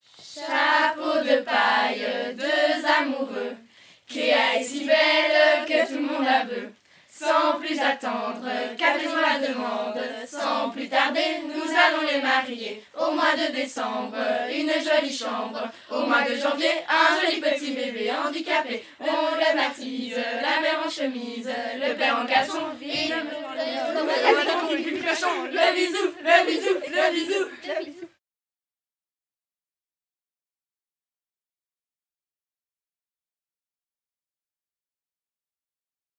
Genre : chant
Type : chant de mouvement de jeunesse
Interprète(s) : Les Scouts de Sibret
Lieu d'enregistrement : Sibret
Ce chant est chanté pendant le repas.
Enregistrement réalisé dans le cadre de l'enquête Les mouvements de jeunesse en chansons.